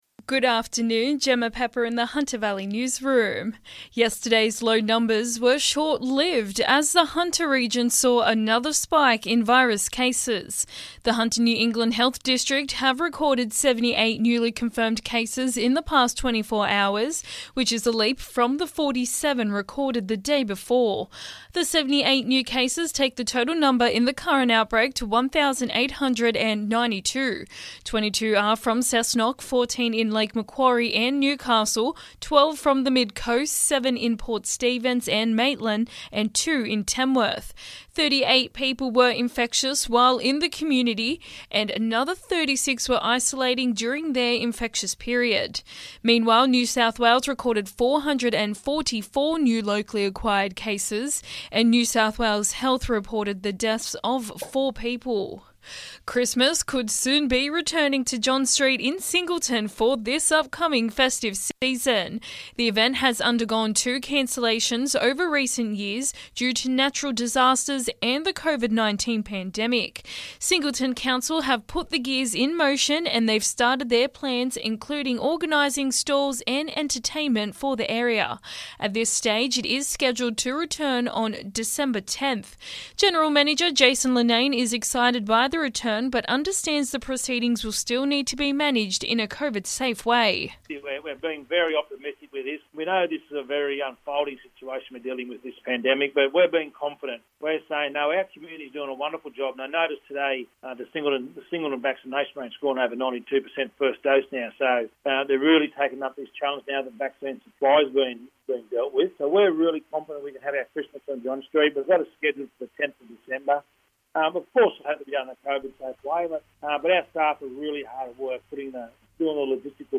Listen: Hunter Local News Headlines 13/10/2021